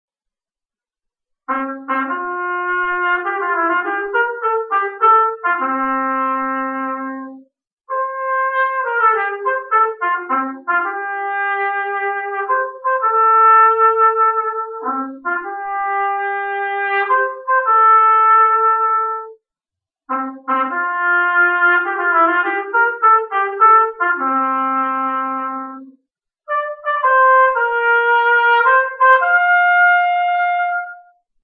Hejnał już w sobotę